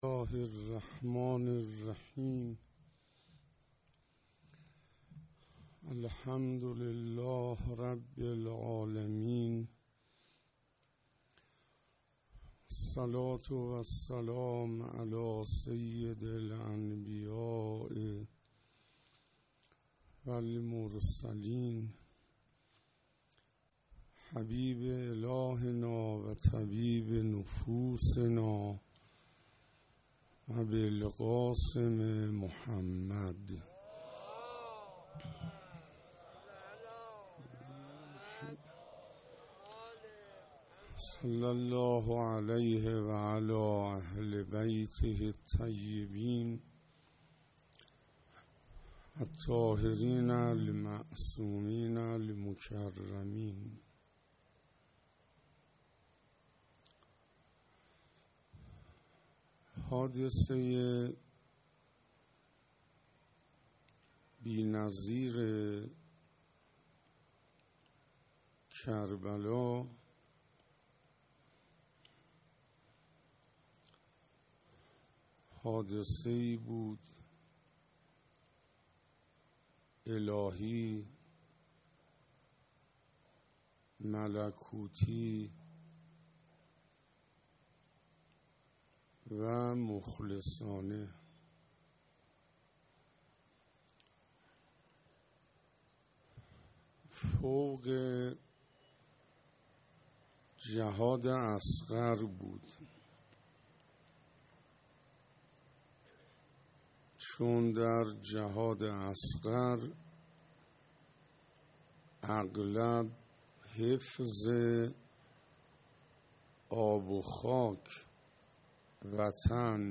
مجموعه صوتی سخنرانی های استاد شیخ حسین انصاریان با موضوع عشق حقیقی دهه اول محرم 1442 در ورزشگاه آیت الله سعیدی